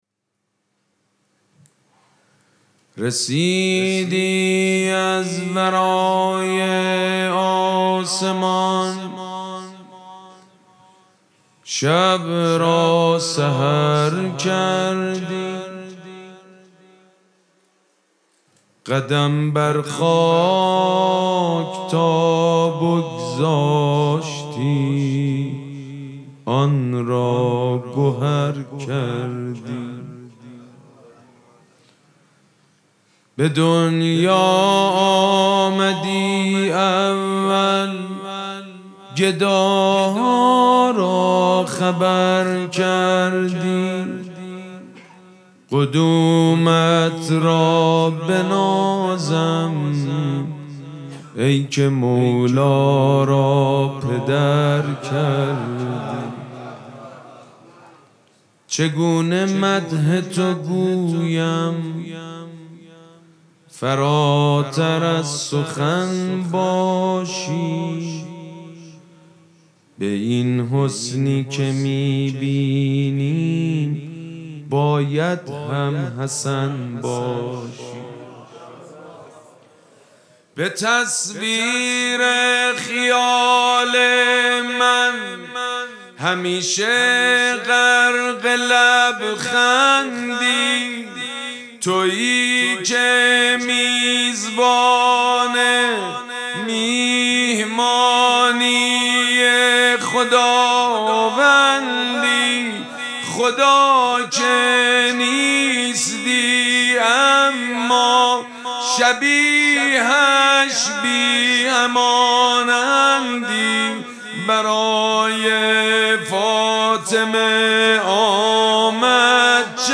مراسم جشن شام ولادت امام حسن مجتبی(ع)
شعر خوانی
حاج سید مجید بنی فاطمه